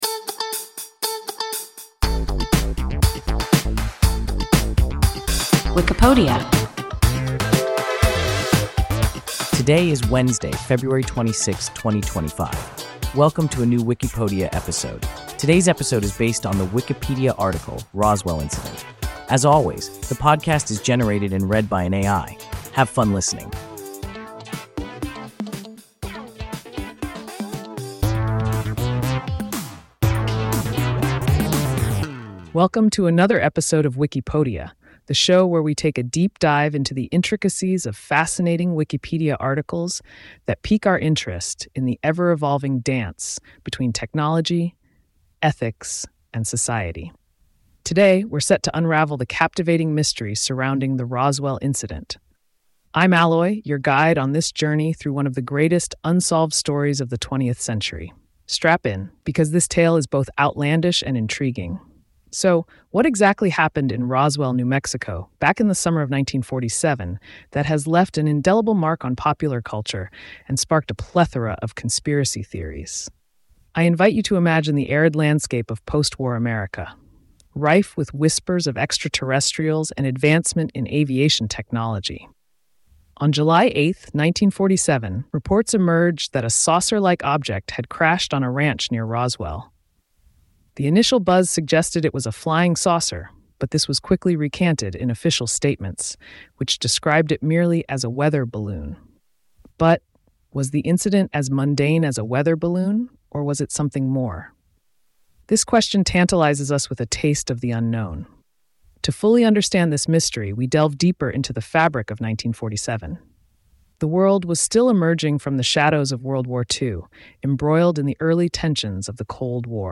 Roswell incident – WIKIPODIA – ein KI Podcast